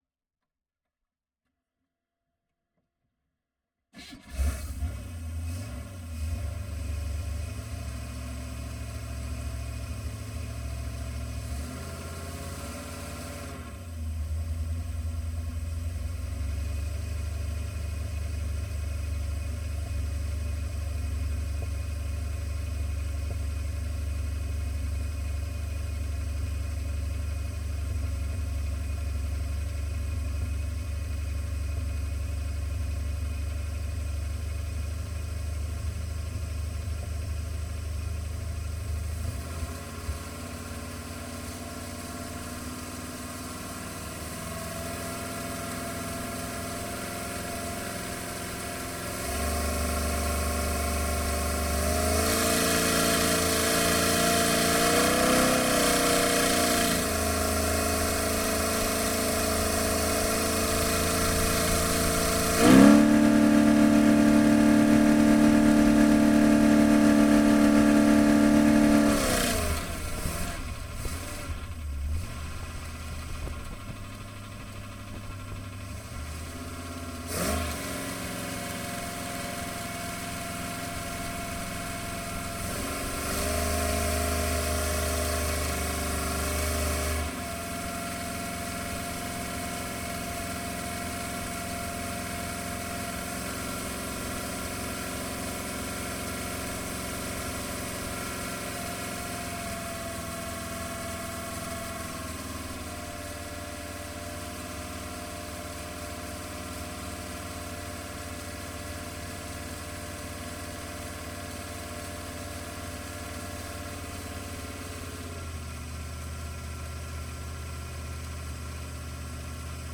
Pole Position - Drag Boat 1970s